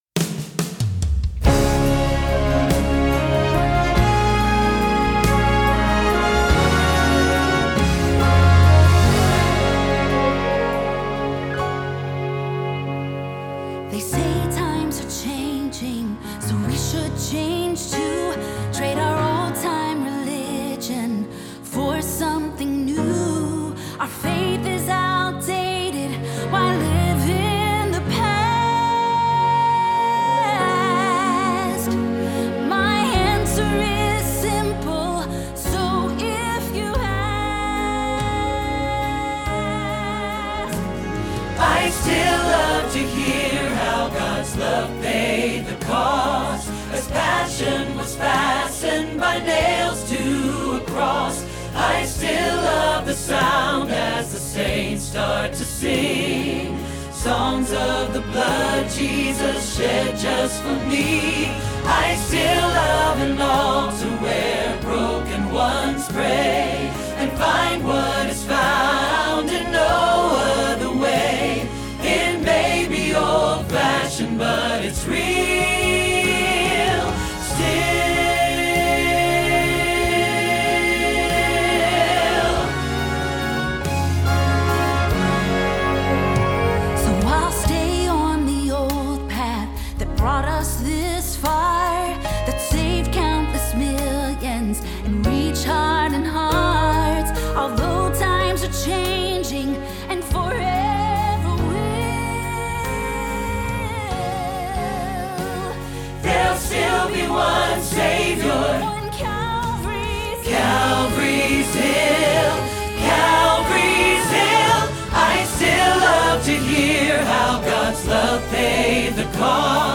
Still – Alto – Hilltop Choir